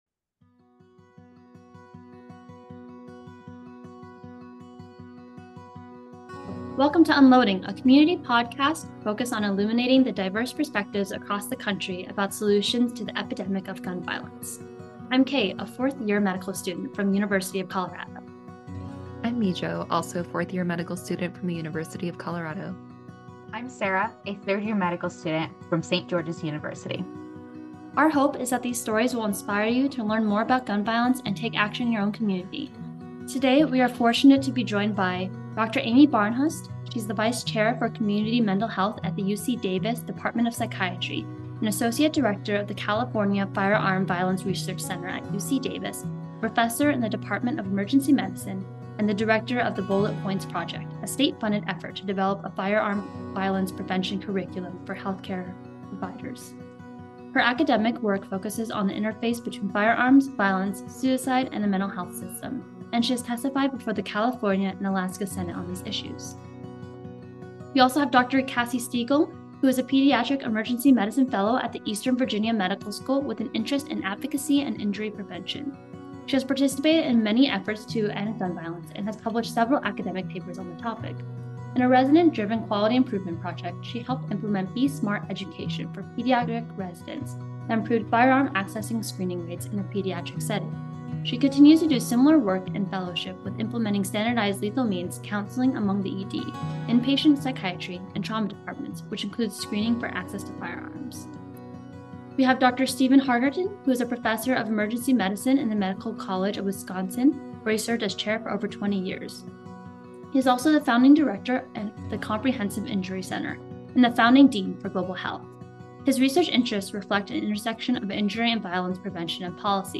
Roundtable Discussion: Rounding with ED Providers